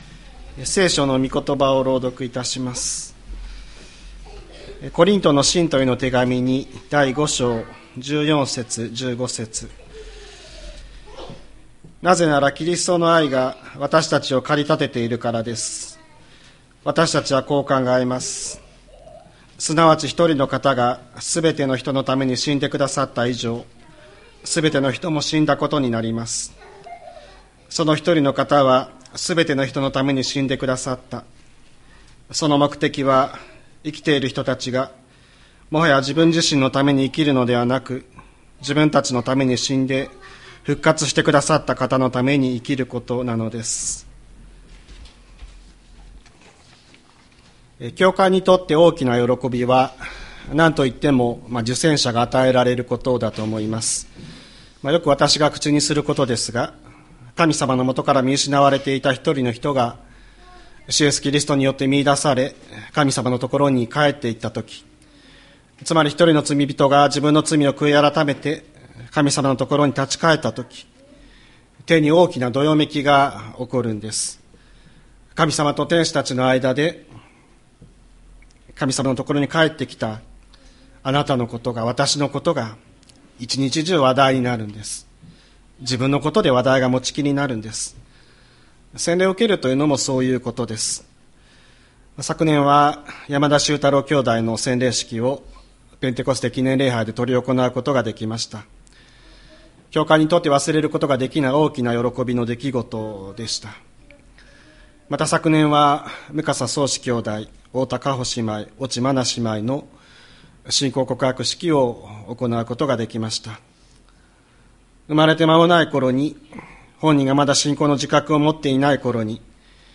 千里山教会 2025年01月26日の礼拝メッセージ。